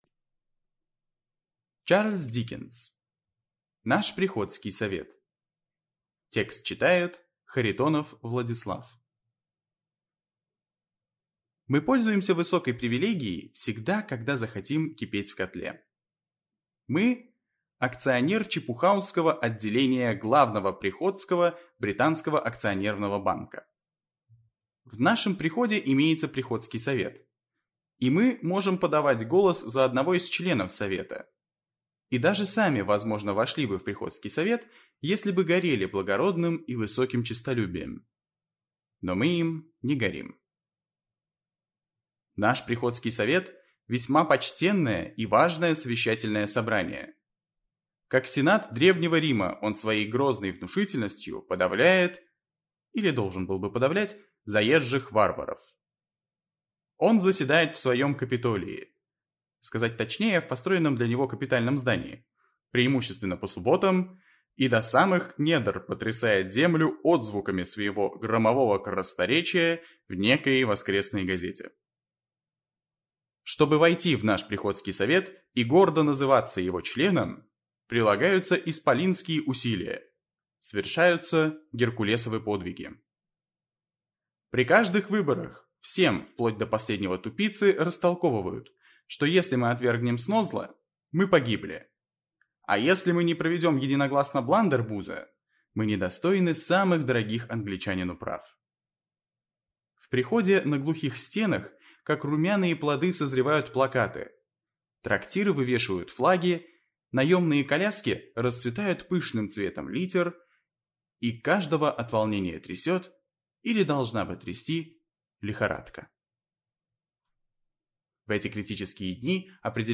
Аудиокнига Наш приходский совет | Библиотека аудиокниг